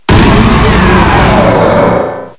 cry_not_mega_steelix.aif